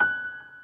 piano_last34.ogg